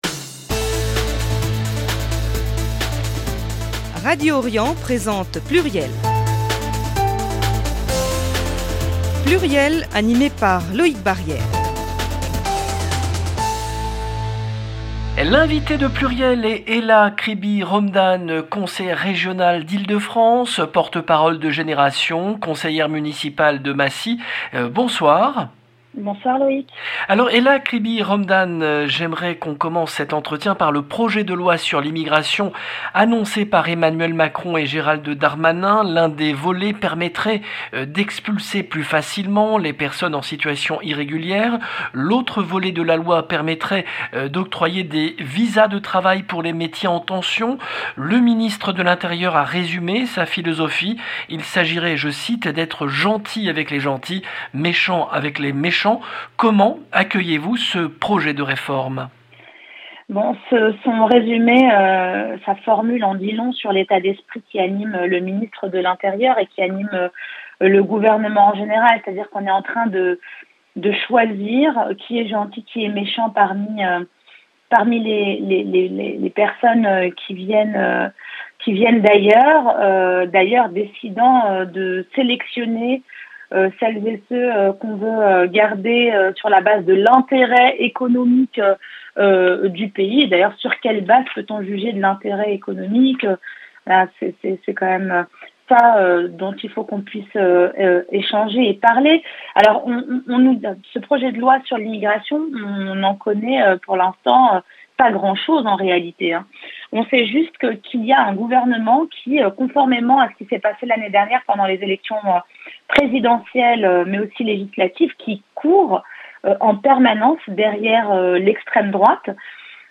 PLURIEL avec Hella Kribi-Romdhane, conseillère régionale, porte-parole de Génération.s
PLURIEL, le rendez-vous politique du mercredi 9 novembre 2022